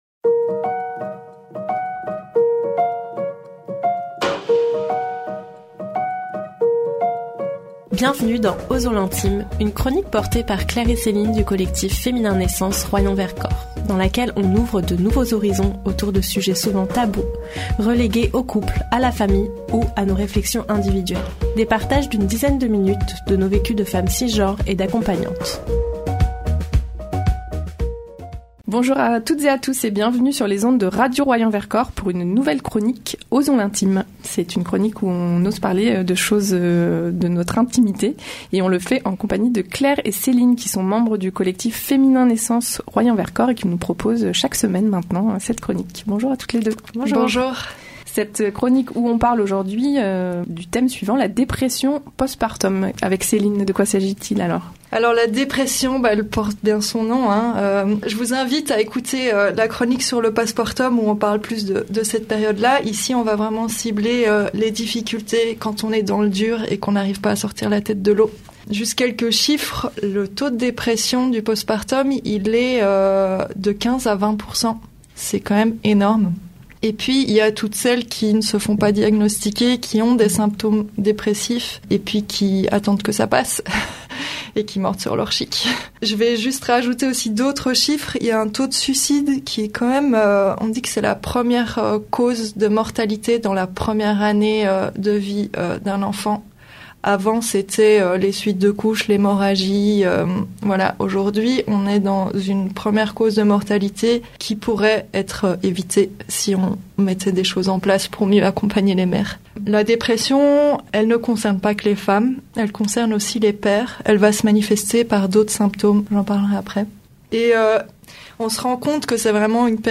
Une nouvelle chronique à découvrir sur les ondes de Radio Royans Vercors : Osons l’intime !